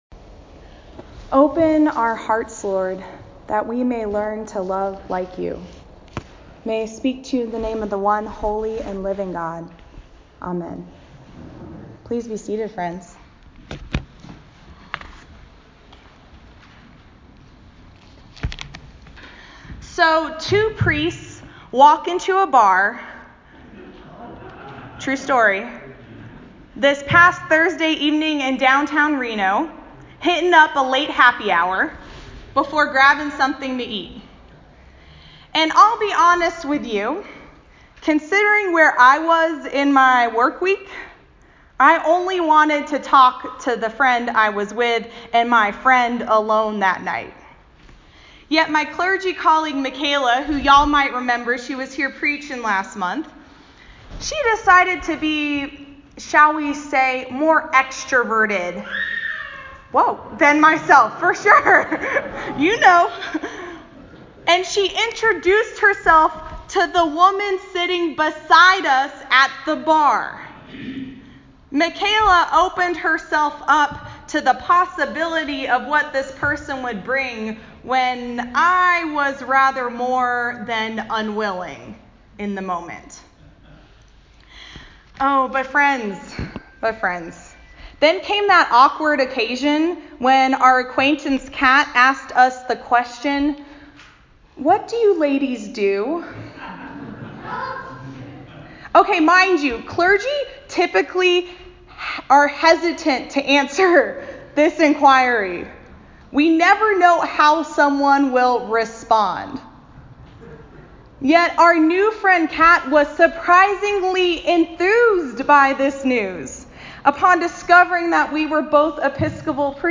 A Sermon for the 17th Sunday after Pentecost Mark 7:24-37 September 9, 2018